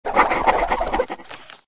SA_writeoff_pen_only.ogg